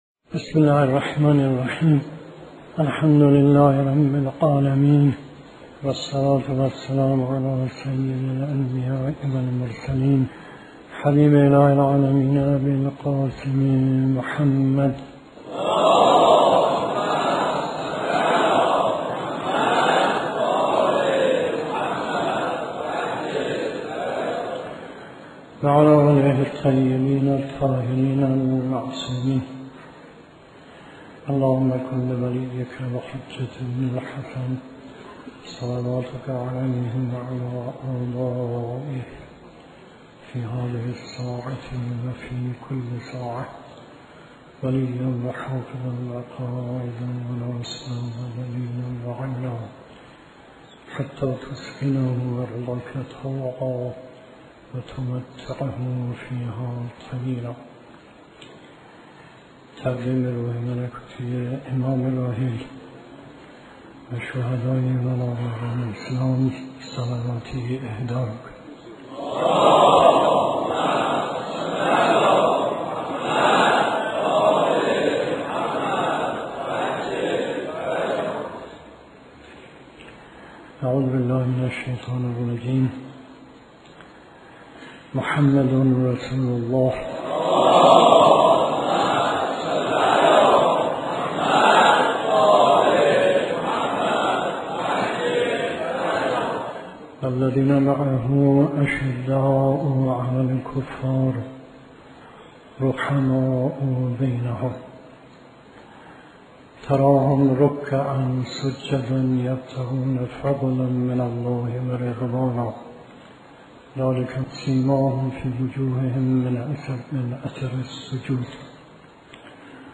سخنرانی آیت الله مصباح یزدی درباره ایمان، معیار وحدت